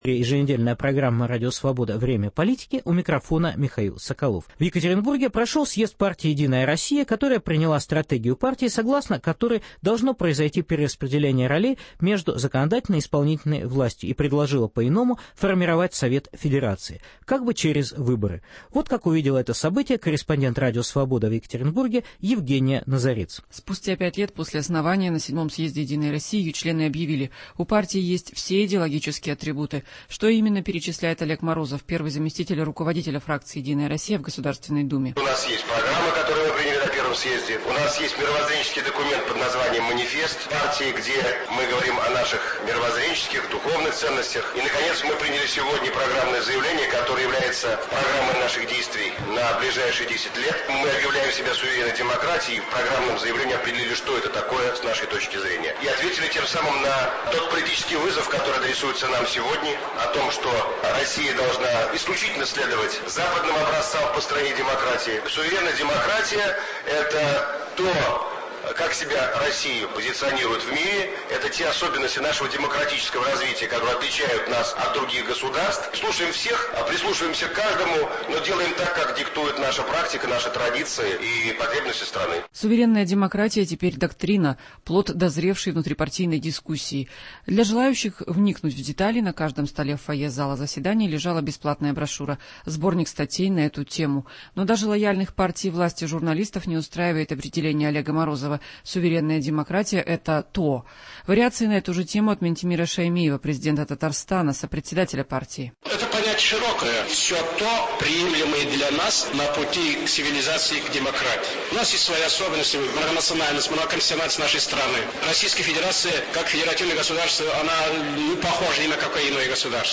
Специальный репортаж со съезда "Единой России".